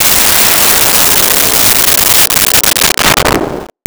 Missle 06
Missle 06.wav